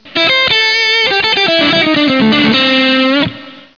מהר